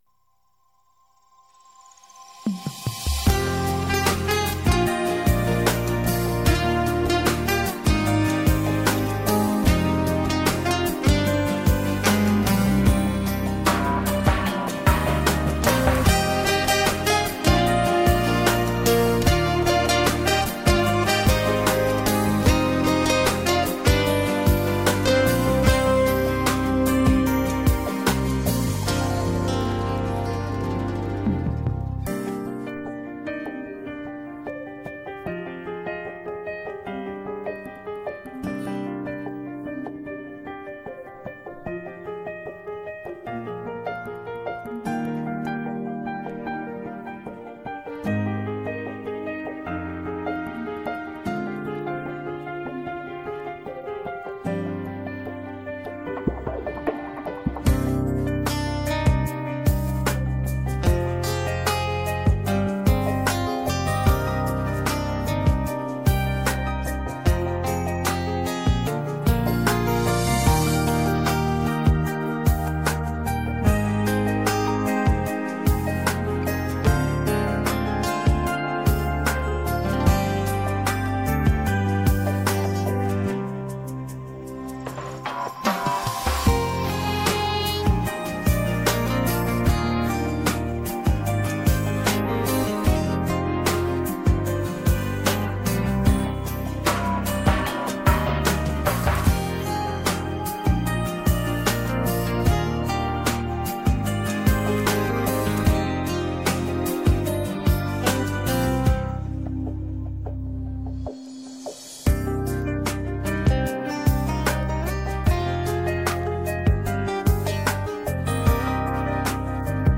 • Категория: Детские песни
Слушать минус